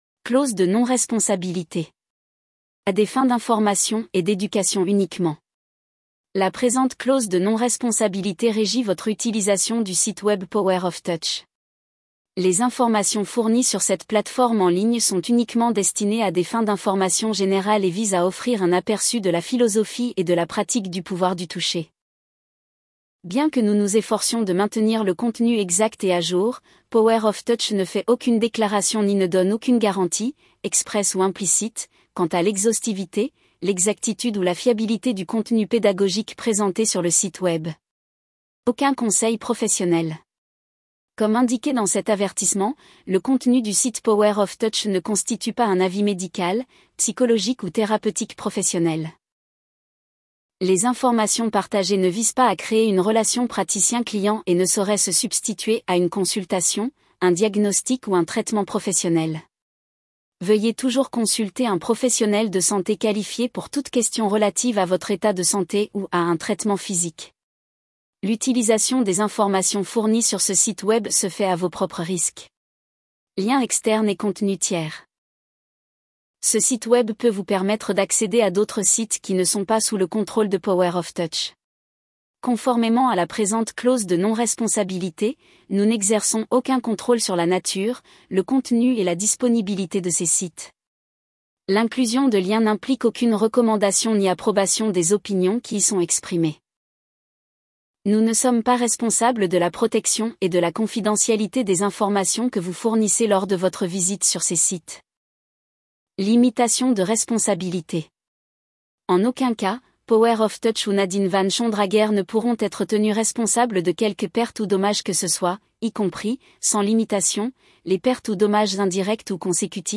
mp3-text-to-voice-clause-de-non-responsabilite-power-of-touch.mp3